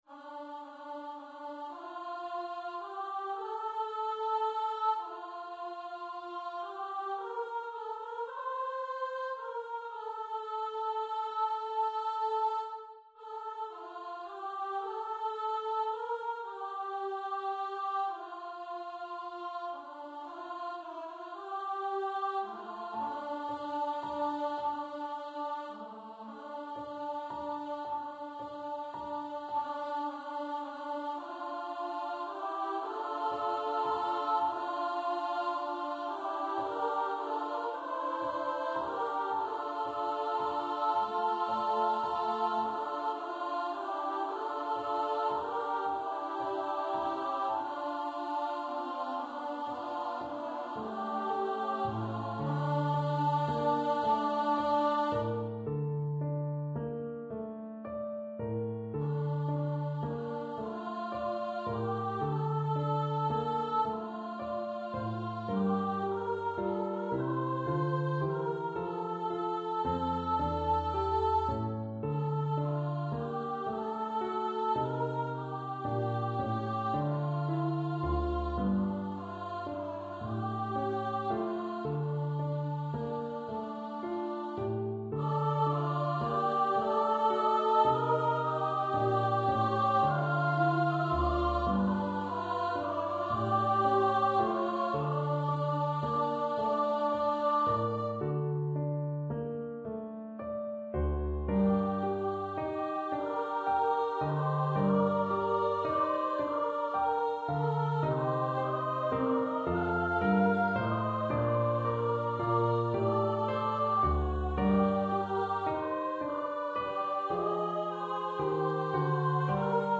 for upper voice choir and piano
Traditional Catalan song
for SSA choir with piano accompaniment.
Choir - 3 part upper voices